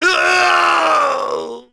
Kaulah-Vox_Dead.wav